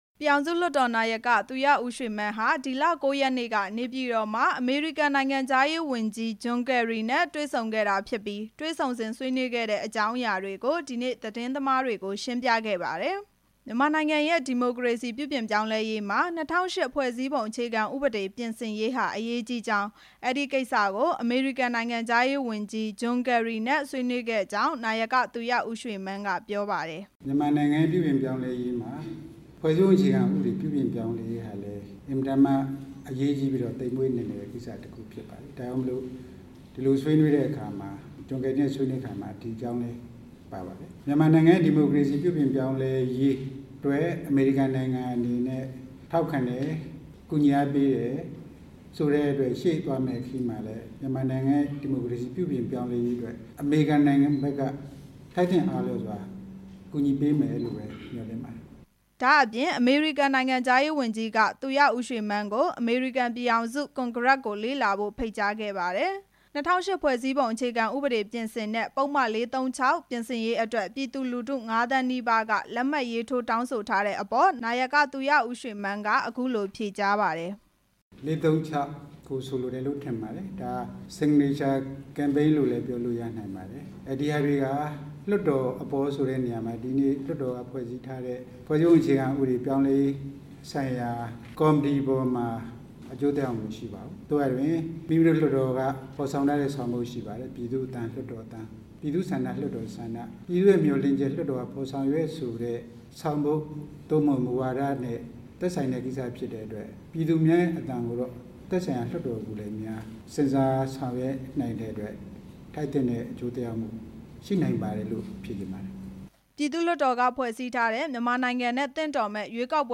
နေပြည်တော် ပြည်သူ့လွှတ်တော် အဆောက်အဦးမှာ သတင်းသမားတွေနဲ့  ဒီနေ့ တွေ့ဆုံစဉ် သူရဦးရွှေမန်း က သတင်းထောက်တစ်ဦးရဲ့ မေးမြန်းချက်ကို ဖြေ ကြားခဲ့တာ ဖြစ်ပါတယ်။